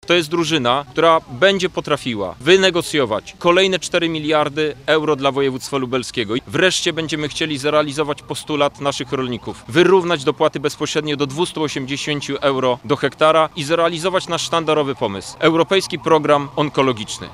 Kandydaci do Europarlamentu z lubelskiej listy Koalicji Europejskiej podsumowali kampanię. Podczas konferencji, lider ugrupowania Krzysztof Hetman przypomniał najważniejsze punkty programowe.